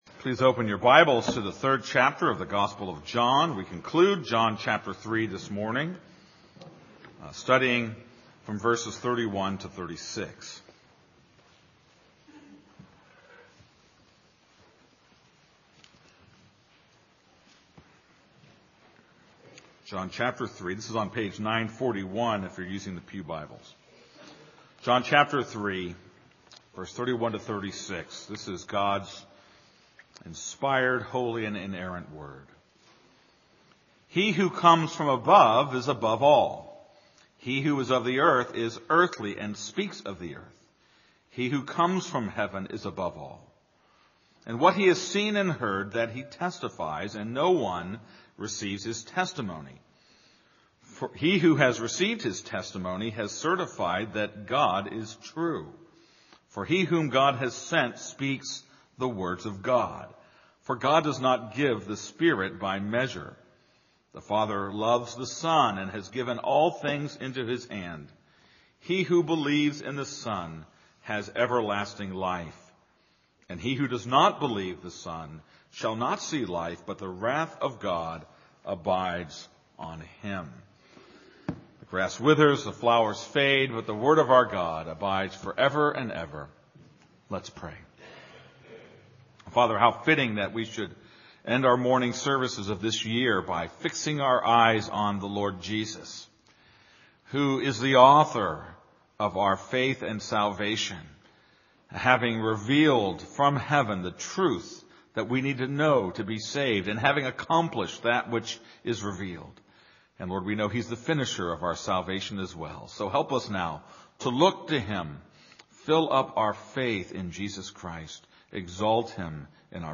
This is a sermon on John 3:31-36.